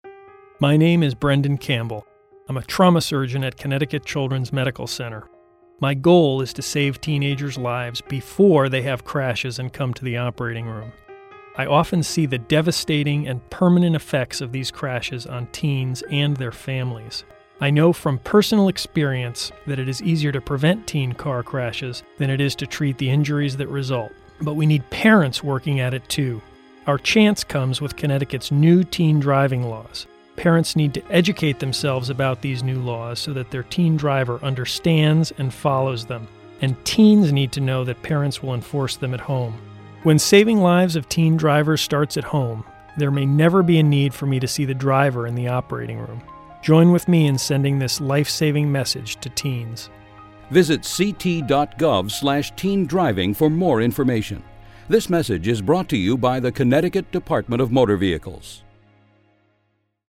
Teen Driving Radio Ads The DMV produced two radio ads to inform listeners of the new teen driving laws.